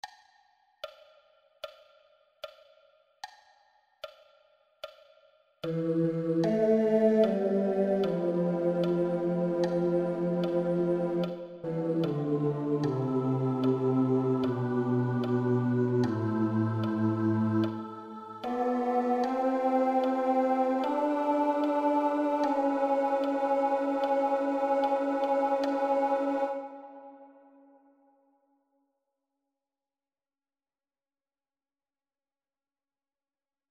Key written in: A Minor